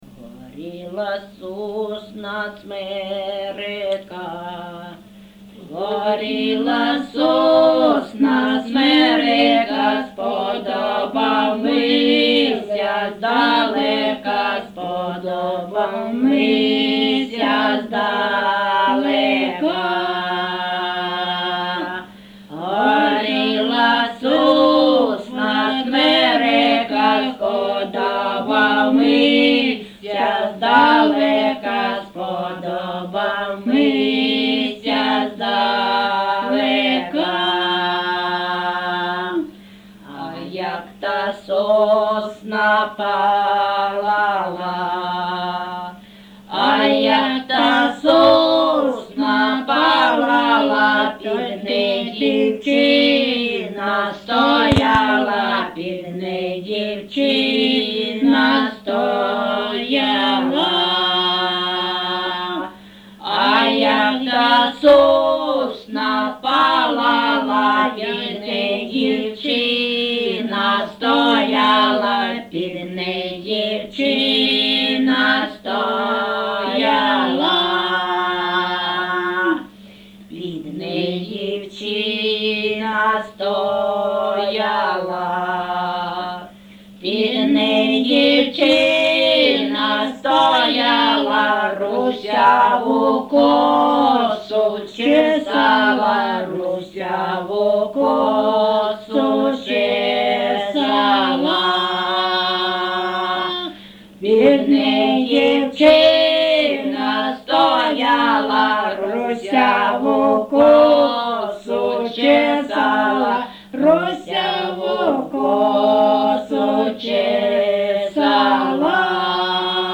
ЖанрПісні з особистого та родинного життя
Місце записум. Старобільськ, Старобільський район, Луганська обл., Україна, Слобожанщина